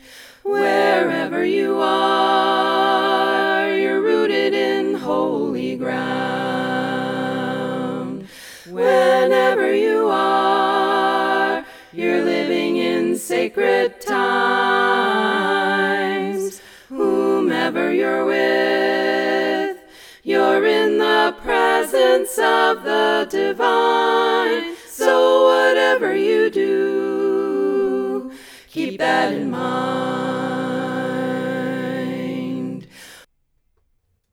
A cappella
SATB